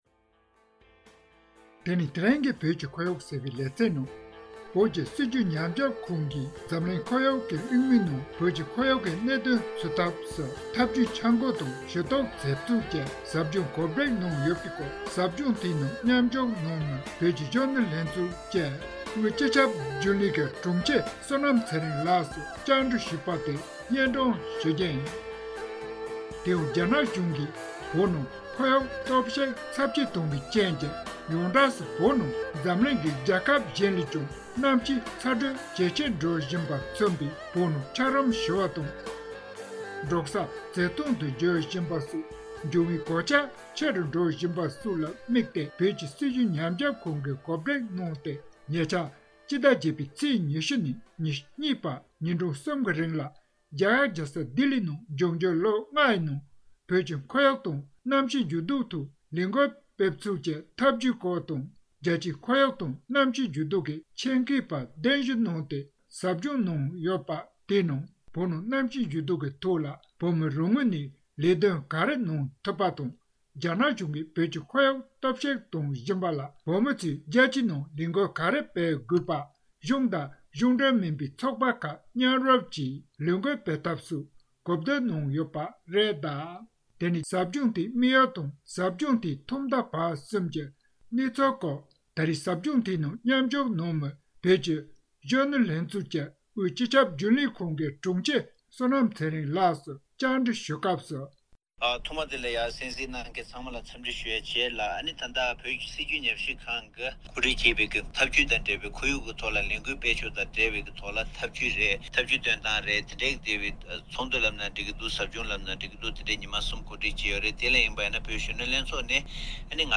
བཅའ་འདྲི་ཞུས་པའི་སྐོར་སྙན་སྒྲོན་ཞུས་པ་དེ་གསན་རོགས་ཞུ།།